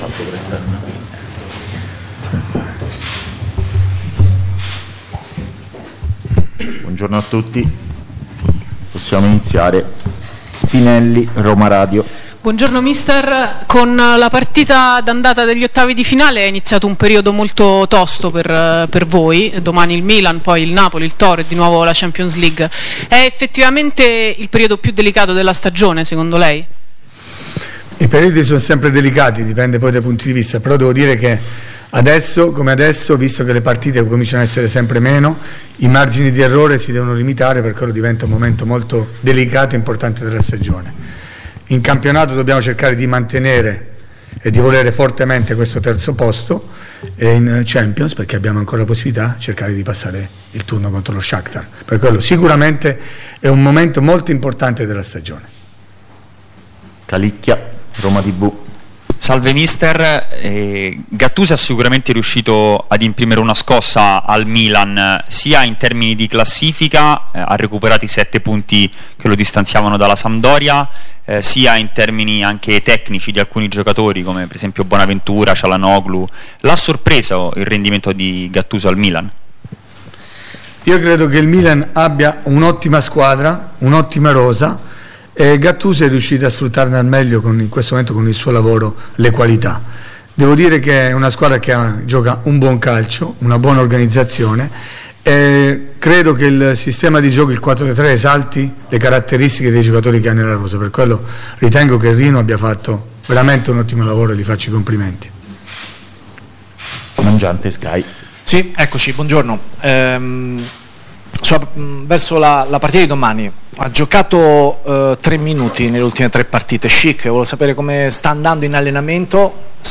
Eusebio Di Francesco, allenatore della Roma, ha parlato in conferenza stampa alla vigilia della sfida di campionato di domani contro il Milan
Conferenze stampa
Conferenza-Di-Francesco-Roma-Milan.mp3